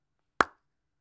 • pop sound
a pop (blop) made with a small plastic surprise shell from a kinder egg.
pop_sound_zDd.wav